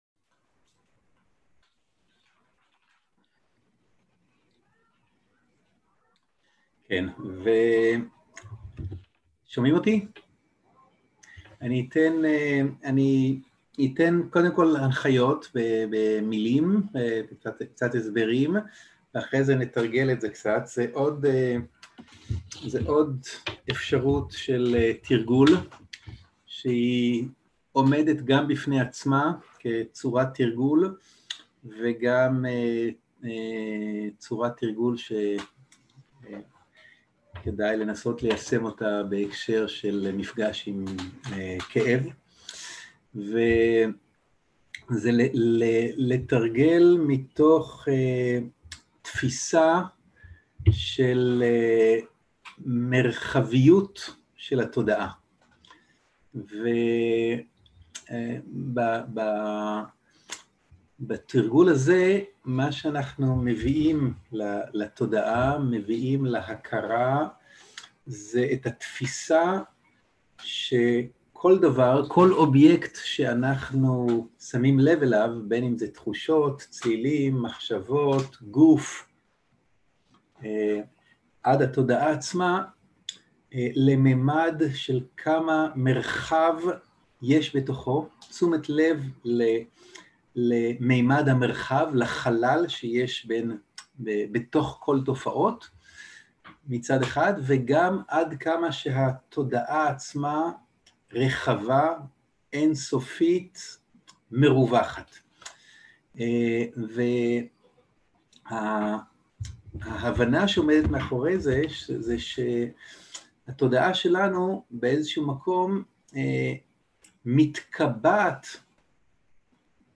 הנחיות מדיטיציה ומדיטציה מונחית - תודעה רחבה
סוג ההקלטה: שיחות דהרמה
עברית איכות ההקלטה: איכות גבוהה מידע נוסף אודות ההקלטה